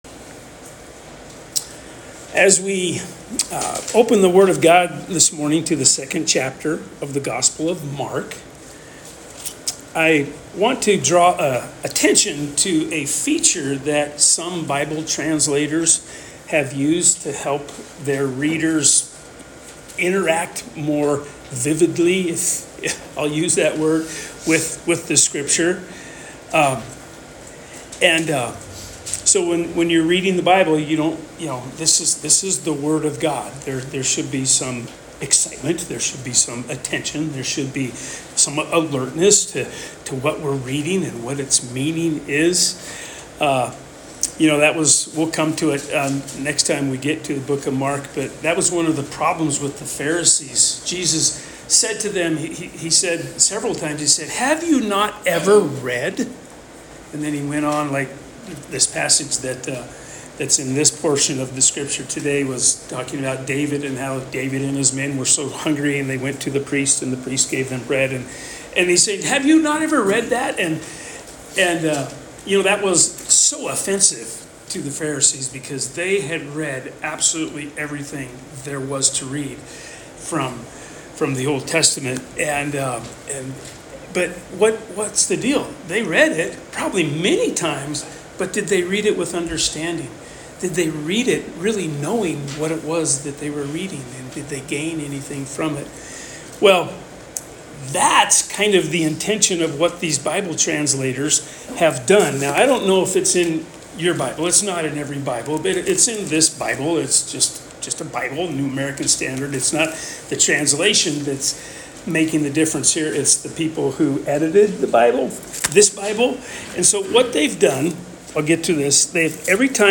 Sermon June 15th, 2025